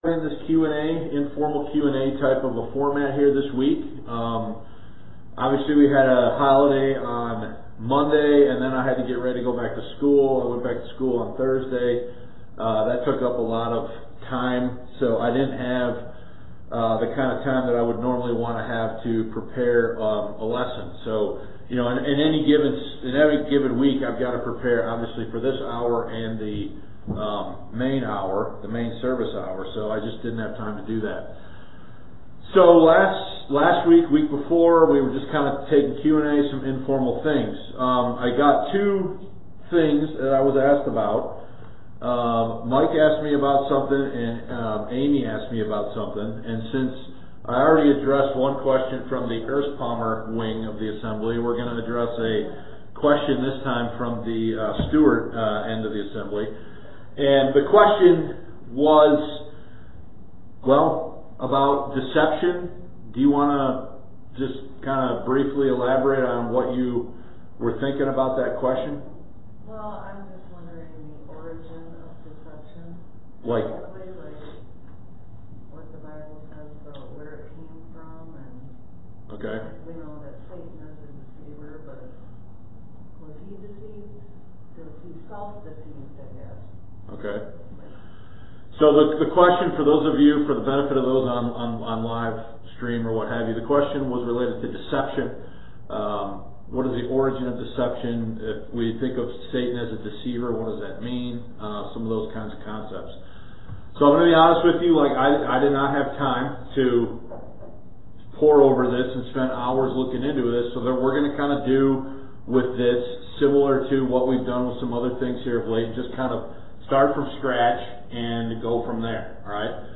Adult Sunday School Q&A: Deception